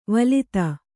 ♪ valita